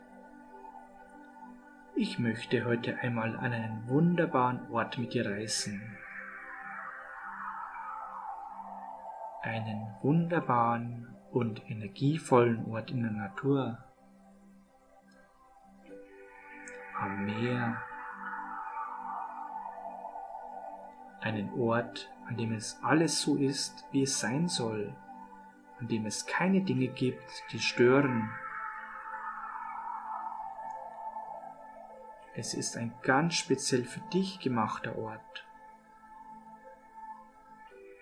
Hörprobe: E3003 – Entspannungshypnose “ Entspannung vom Alltag – Schöpfen Sie neue Kraft und Energie (Teil 2)“